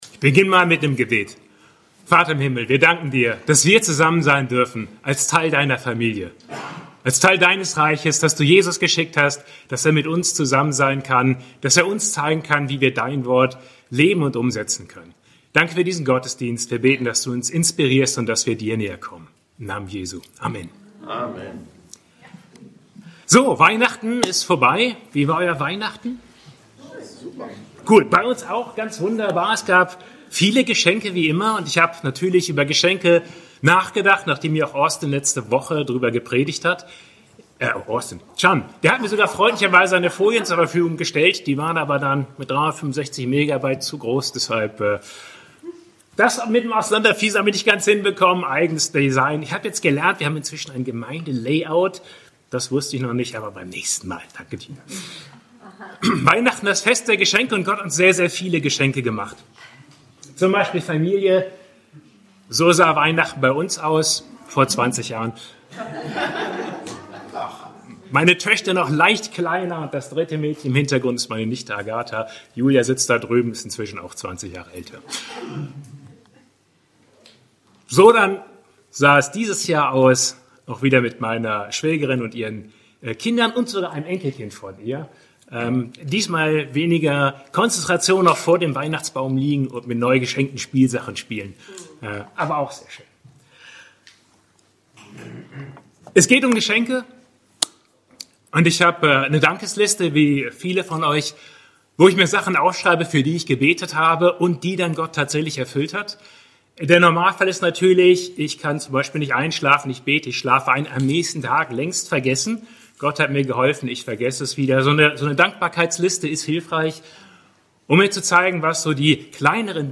Gottes Wort in einem Jahr (2026) lesen ~ BGC Predigten Gottesdienst Podcast